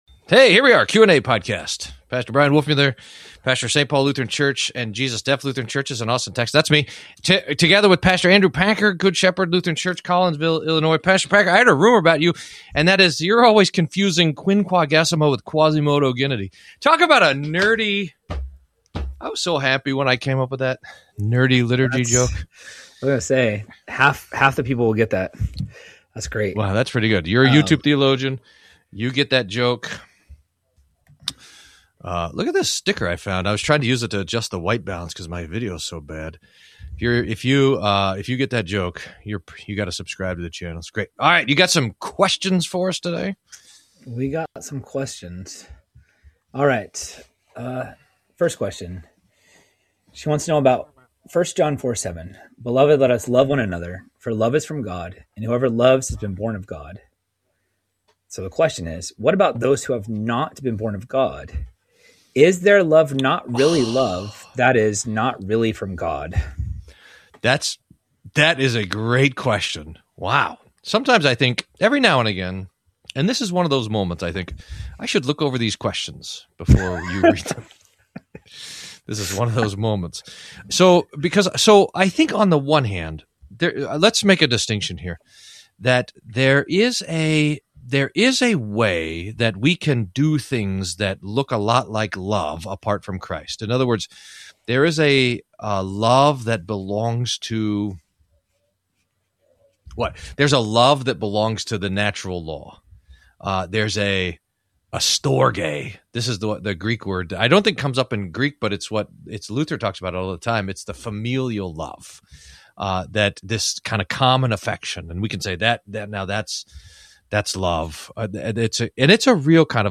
Theology Q&A Q&A: Can we love apart from God?